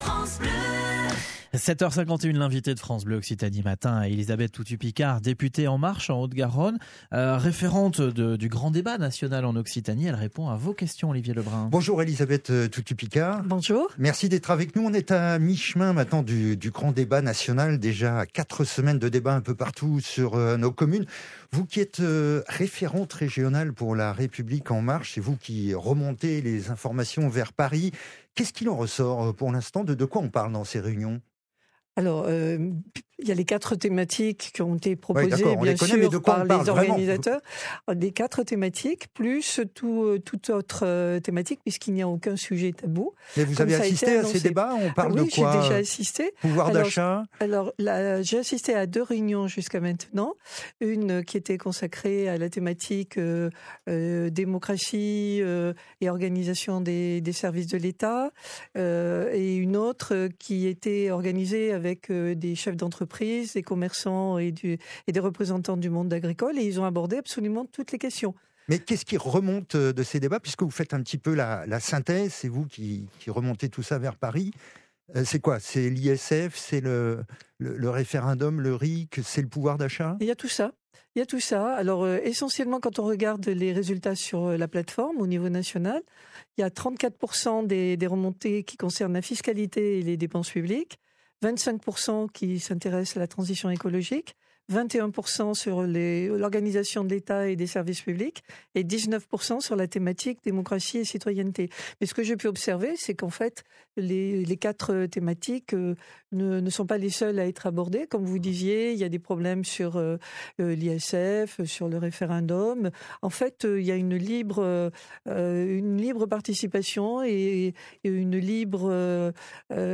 Vendredi 15 février, Elisabeth Toutut-Picard était l’invitée de France Bleu Occitanie Toulouse pour une interview en direct sur les sujets d’actualité.